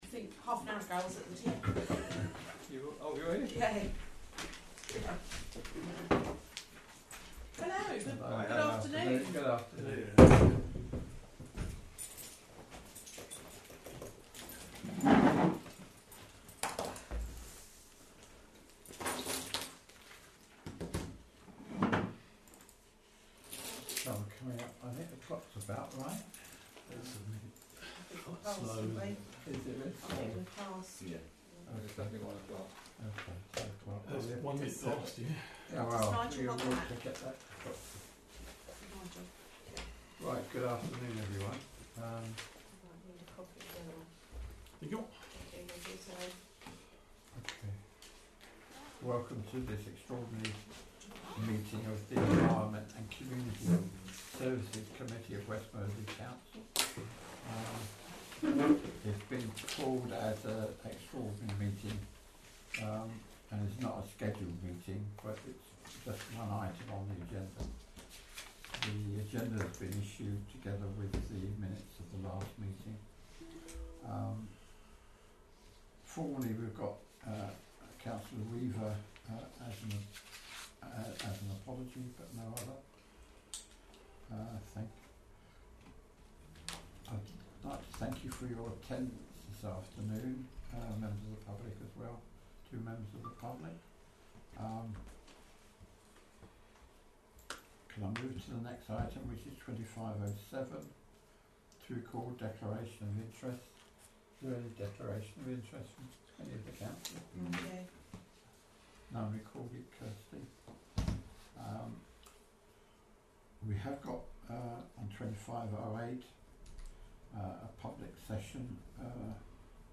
Council Meeting